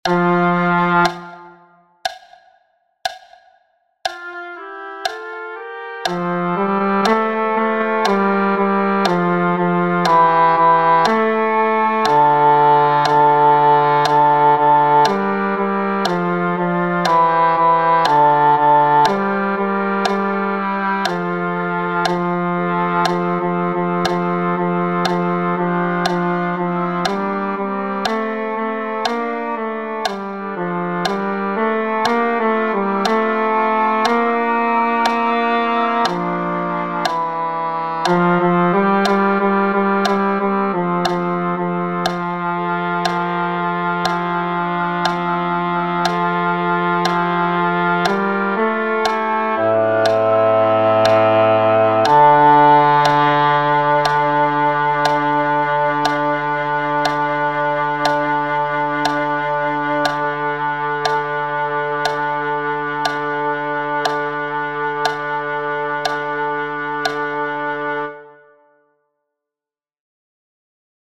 La Rosa Bianca - Contralti